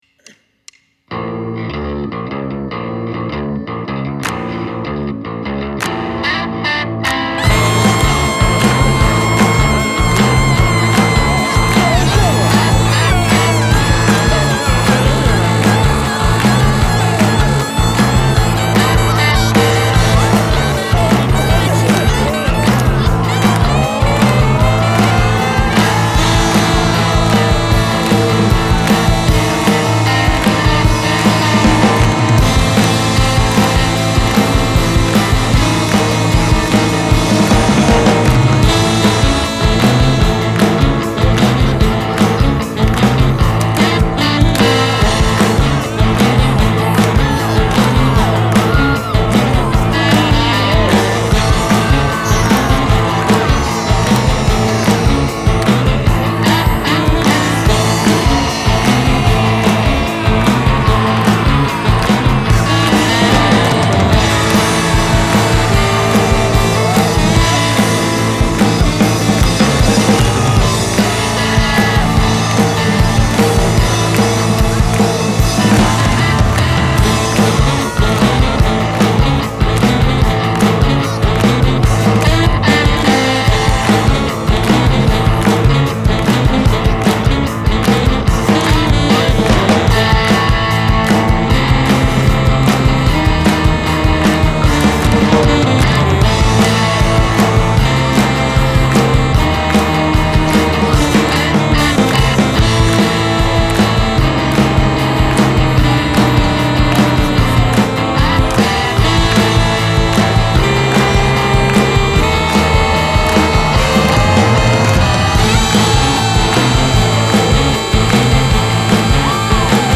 fun rock’n’roll
experimental, doo-wop, atonal